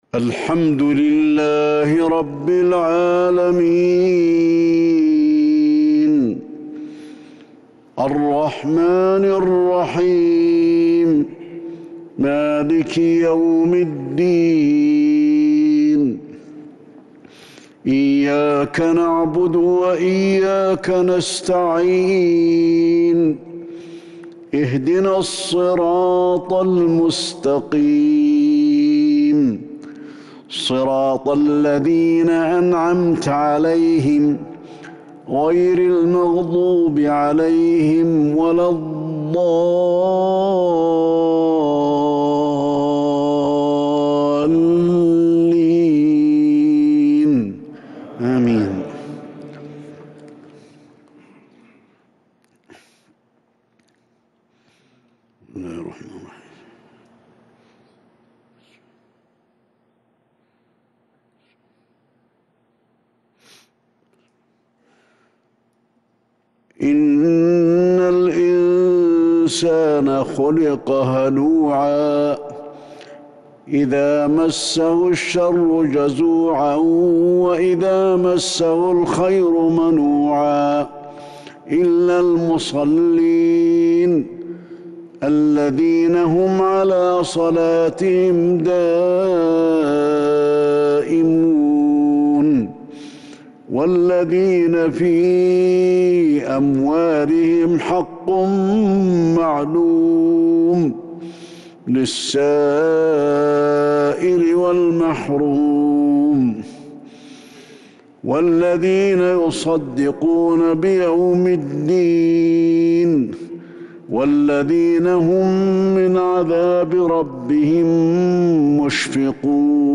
صلاة المغرب من سورة المعارج 6-7-1442 Maghrib prayer from Surat Al-Maarij 18/2/2021 > 1442 🕌 > الفروض - تلاوات الحرمين